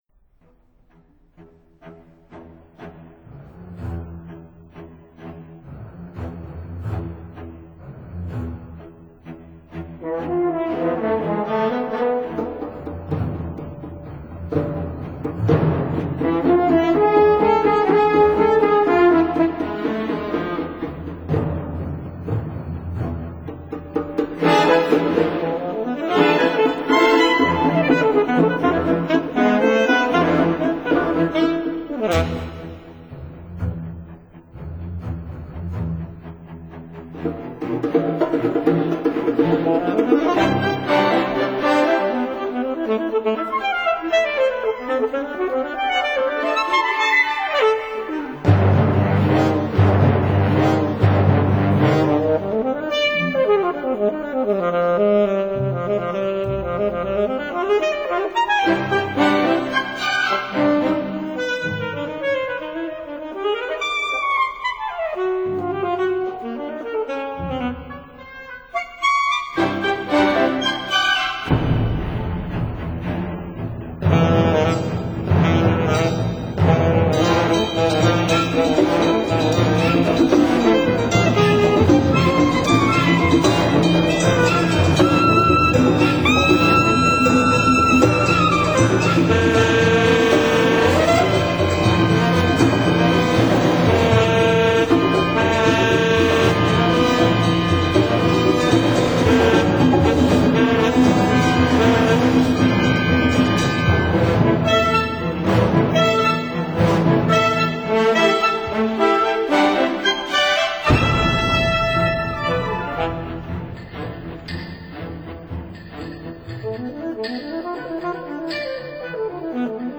薩克斯風
Concerto For Alto Saxophone And Orchestra